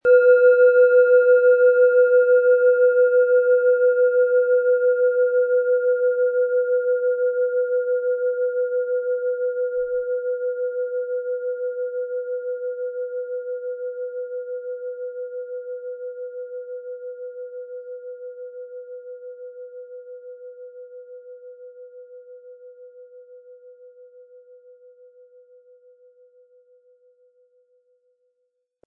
Planetenschale® Lebenslustig sein & Freudig sein mit Delfin-Ton, Ø 10,7 cm, 180-260 Gramm inkl. Klöppel
Planetenton 1
Im Sound-Player - Jetzt reinhören können Sie den Original-Ton genau dieser Schale anhören.
MaterialBronze